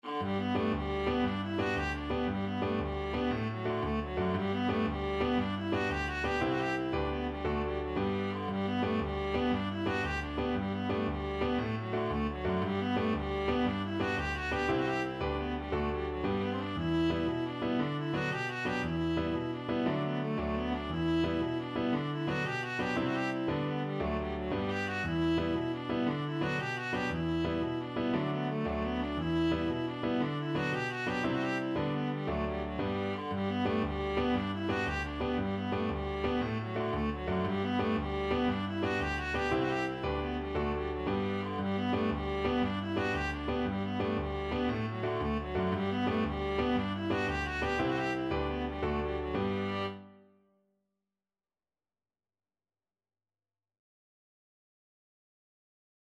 Viola
6/8 (View more 6/8 Music)
G major (Sounding Pitch) (View more G major Music for Viola )
With energy .=c.116
Irish
saddle_the_pony_VLA.mp3